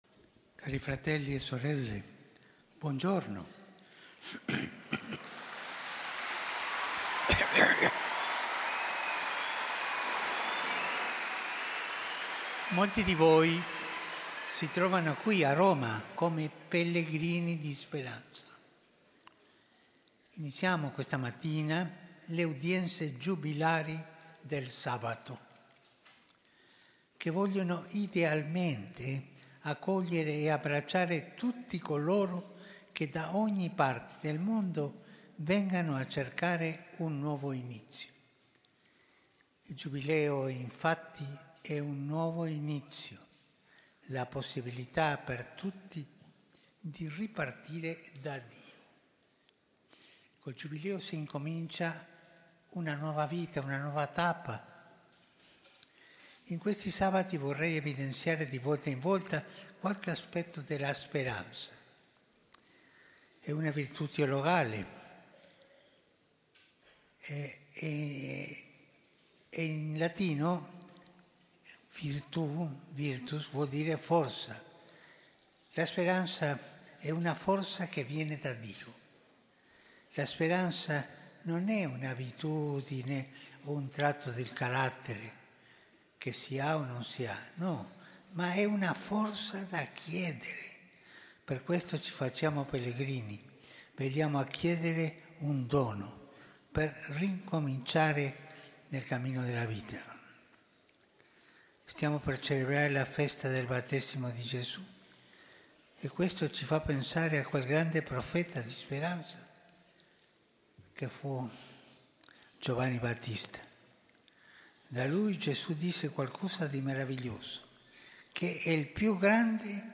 FROM THE PAUL VI HALL, JUBILEE AUDIENCE OF POPE FRANCIS ON THE OCCASION OF THE JUBILEE 2025